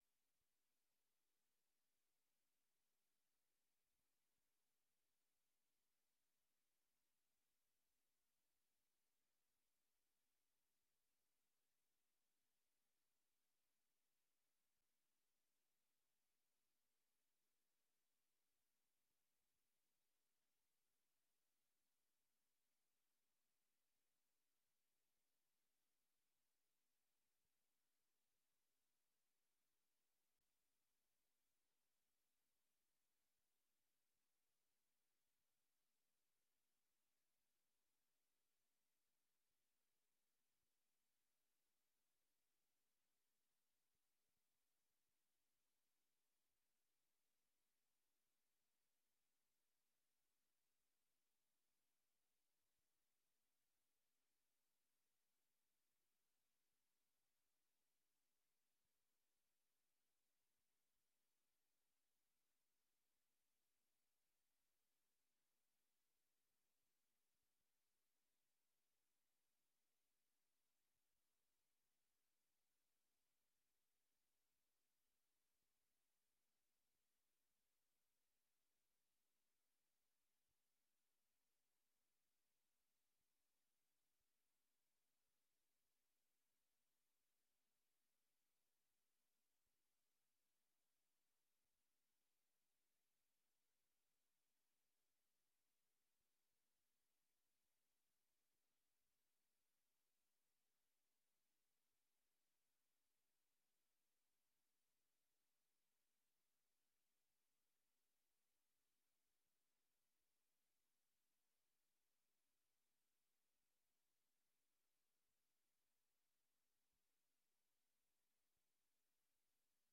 Beeldvormende vergadering 08 december 2022 19:30:00, Gemeente Dronten
Locatie: Raadzaal